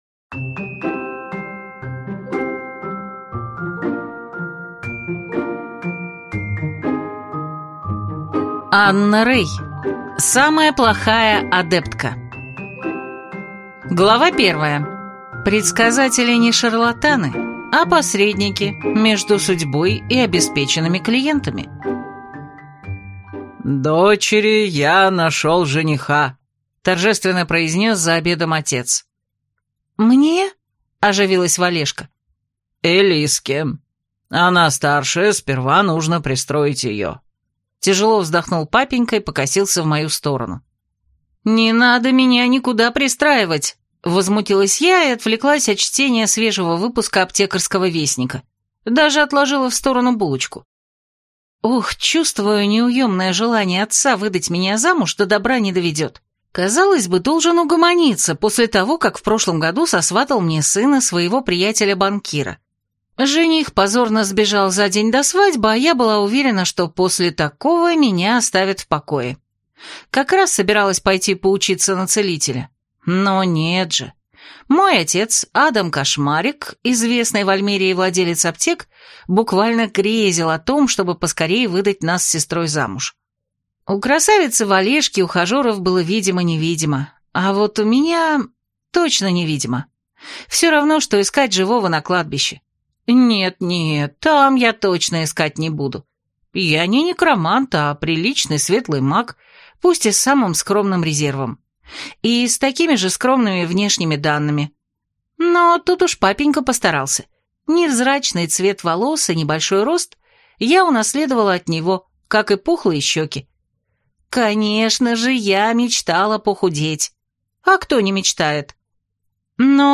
Прослушать фрагмент аудиокниги Самая плохая адептка Анна Рэй Произведений: 7 Скачать бесплатно книгу Скачать в MP3 Вы скачиваете фрагмент книги, предоставленный издательством